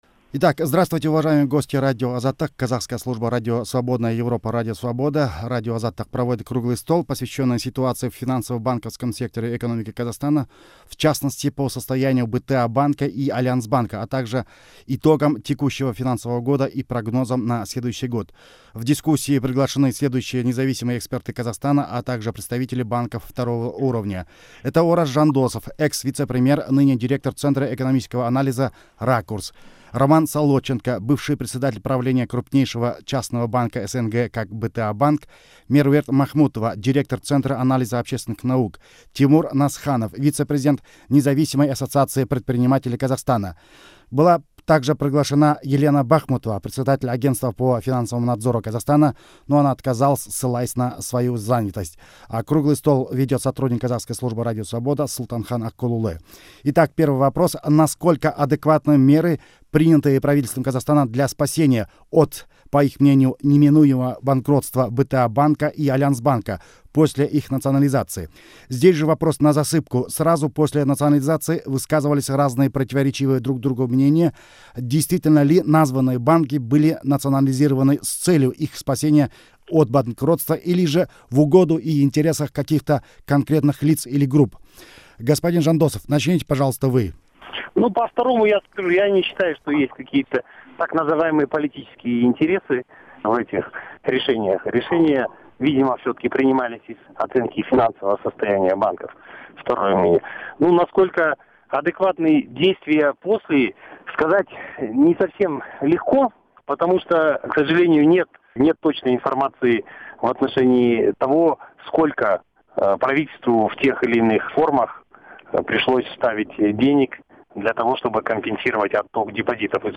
Круглый стол.